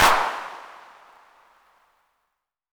808CP_TapeSat.wav